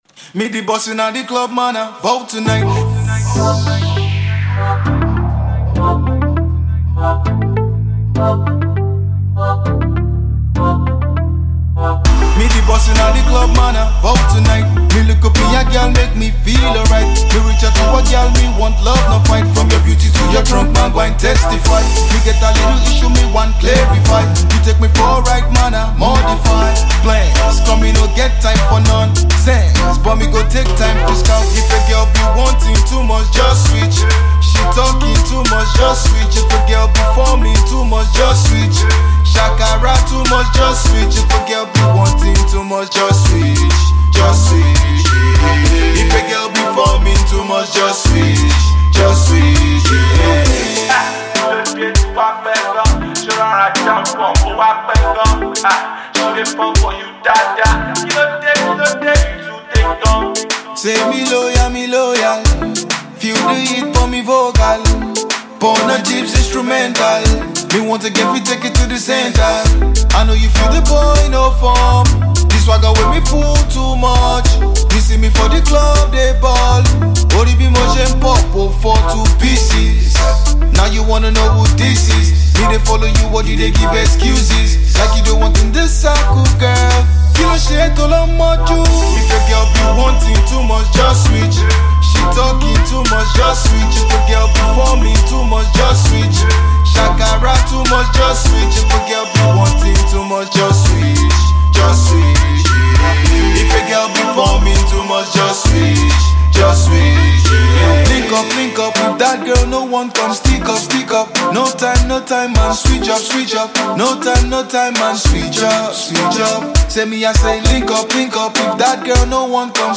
street anthem
amazing vibes that will always make you dance and sing along